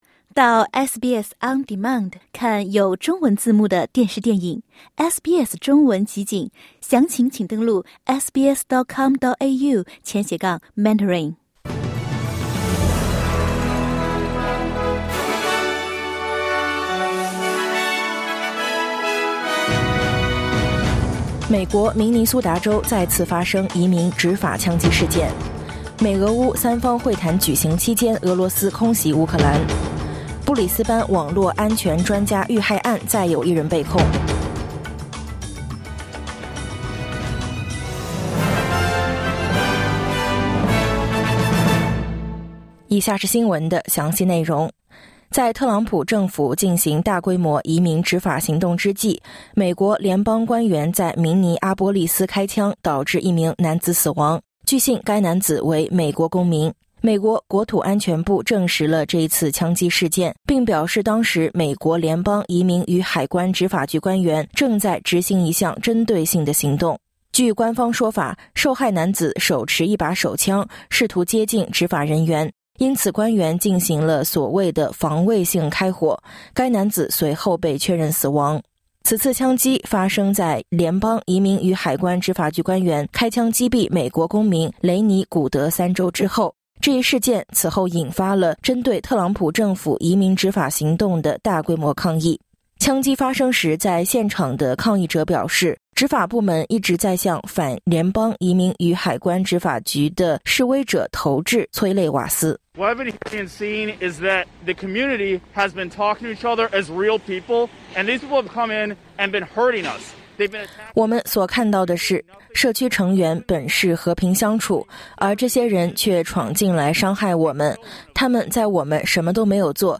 【SBS早新闻】美国明尼苏达州再发移民执法枪击事件
SBS Mandarin morning news Source: Getty / Getty Images